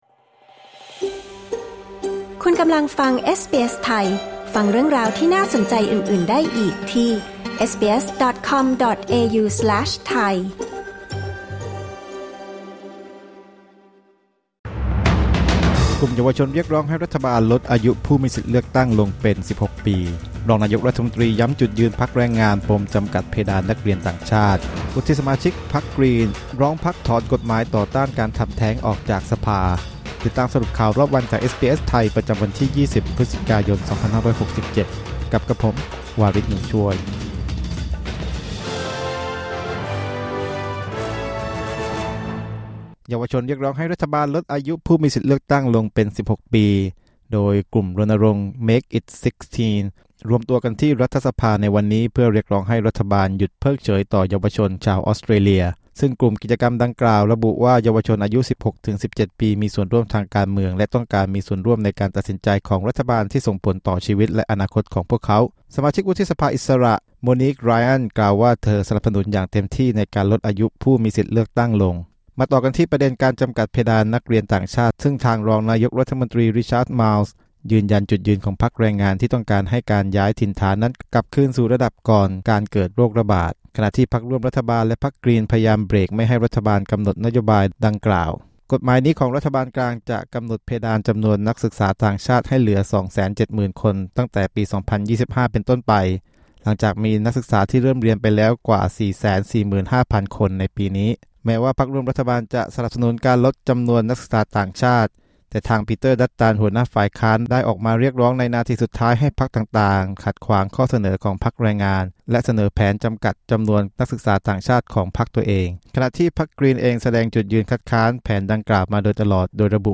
สรุปข่าวรอบวัน 20 พฤศจิกายน 2567